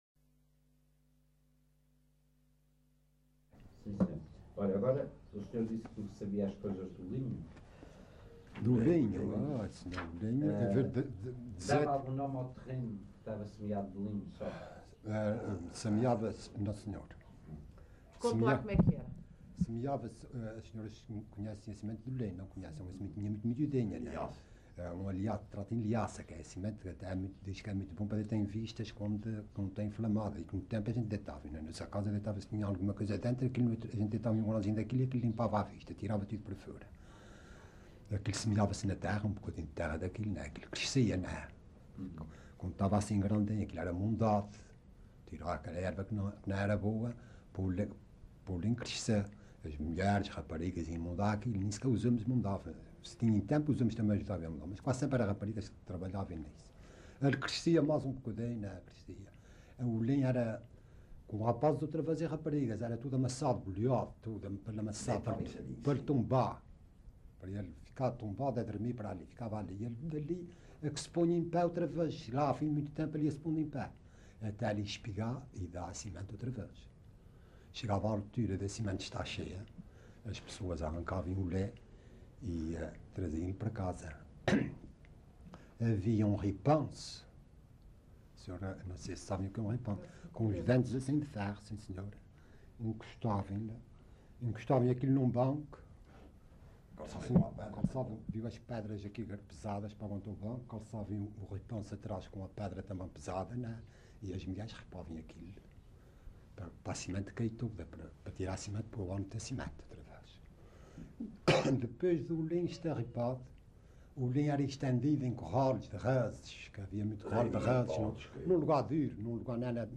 LocalidadePonta Garça (Vila Franca do Campo, Ponta Delgada)